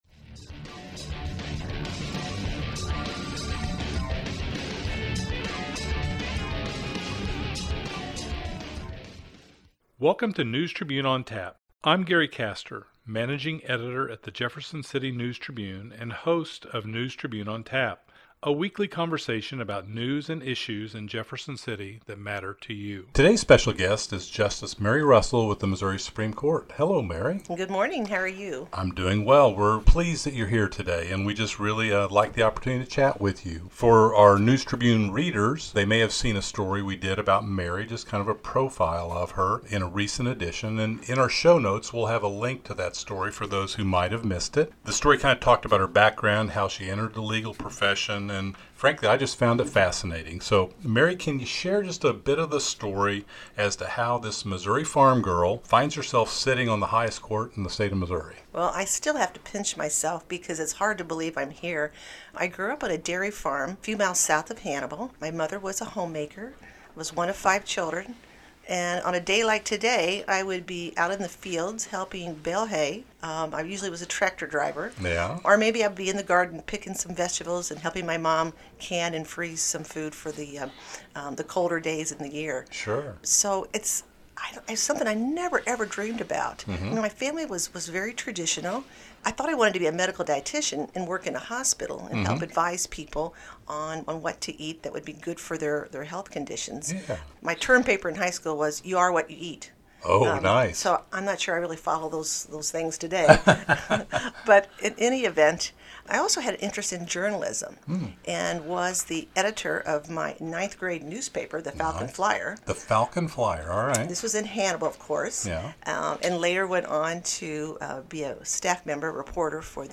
chats with Mary Russell about her journey from farm girl to reporter to Missouri Supreme Court justice, how she's navigated the male-dominated field, and the challenges and opportunities the court system faces.